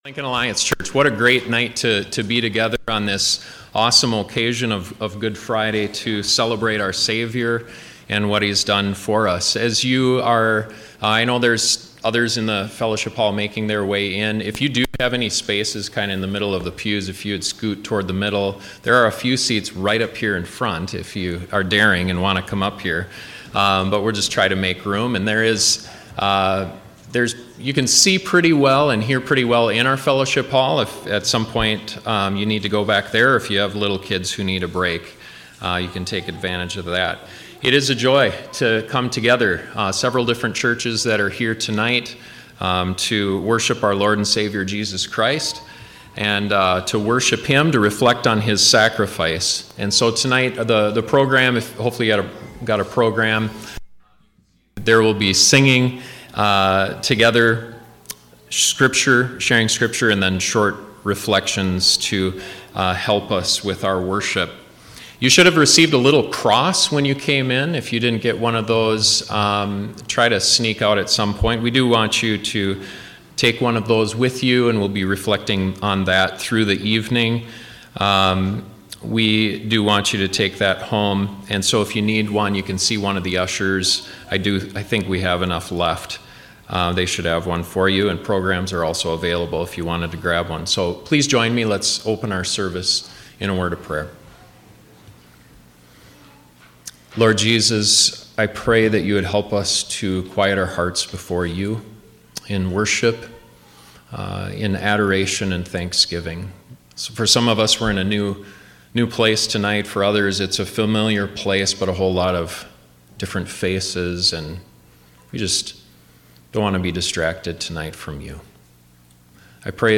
Several local pastors share about Christ’s victory at the cross.